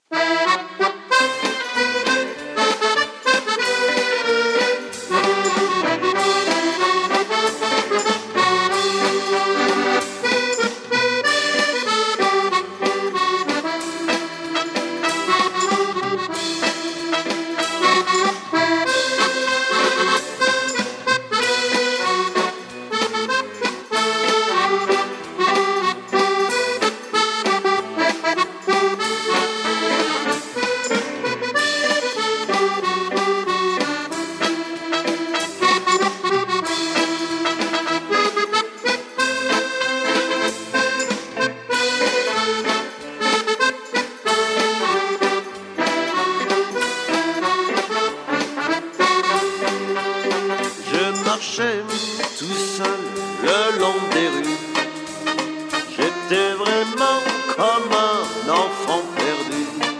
(Fox-trot)